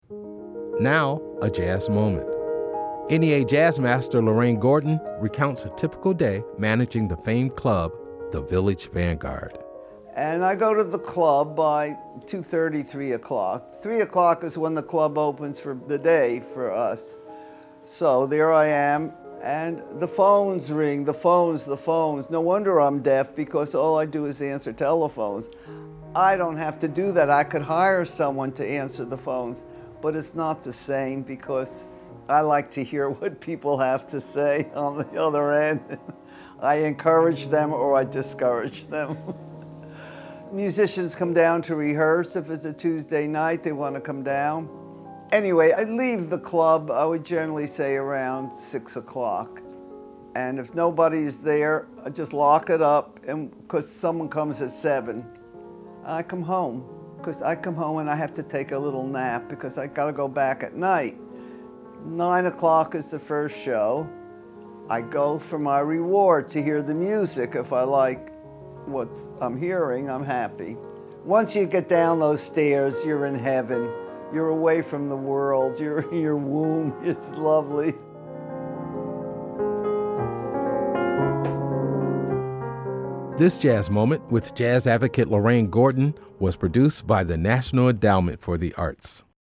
In the Wee Small Hours of the Morning up, under
NEA JAZZ MASTER LORRAINE GORDON RECOUNTS A TYPICAL DAY MANAGING THE FAMED CLUB, THE VILLAGE VANGUARD.
Excerpt of "In the Wee Small Hours of the Morning” composed by David Mann and performed by Fred Herschfrom his album, Alone at the Vanguard, used by permission of Palmetto Records and used by permission of MPL Communications.